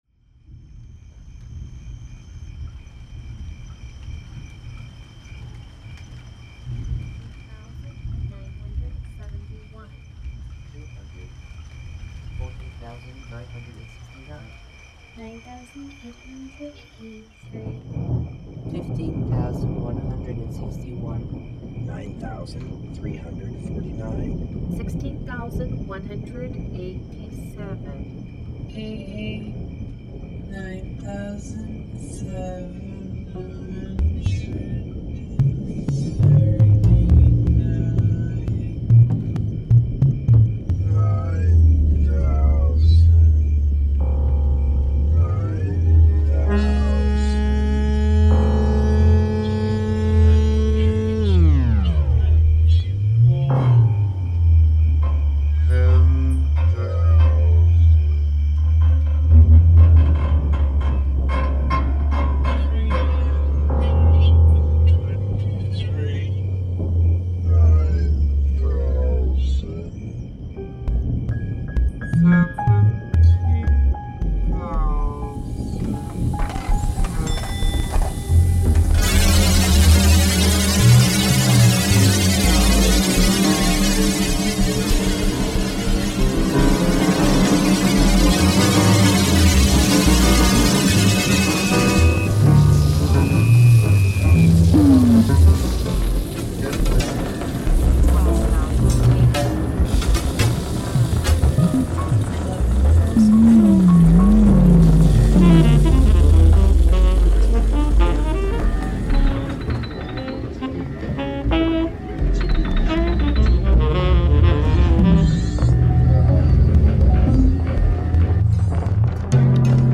A late night live music show with teeth (and horns).